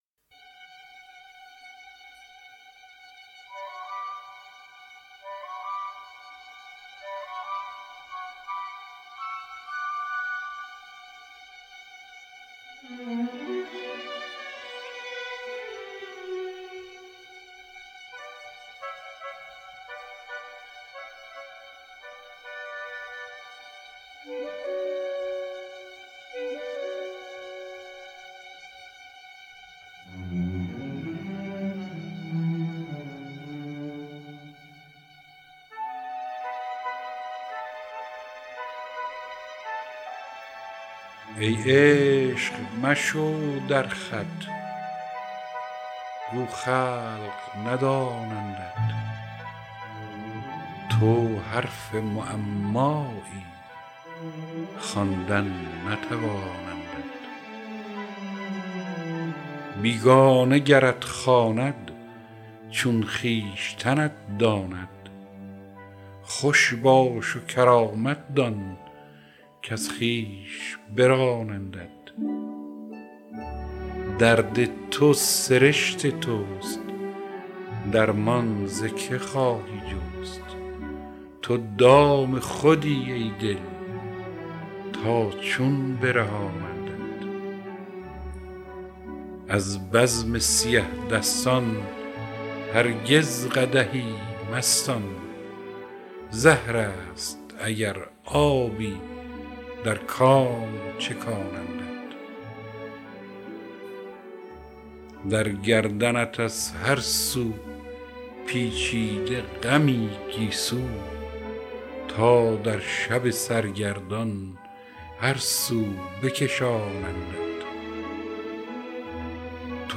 دانلود دکلمه چشمه خارا با صدای هوشنگ ابتهاج
گوینده :   [هوشنگ ابتهاج]